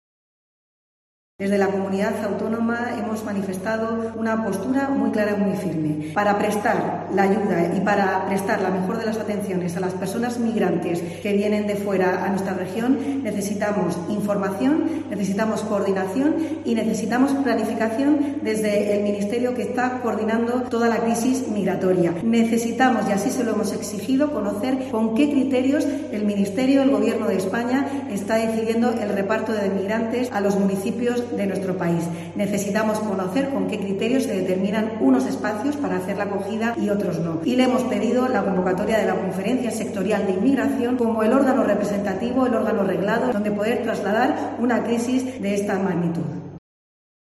Conchita Ruiz, consejera de Política Social, Familias e Igualdad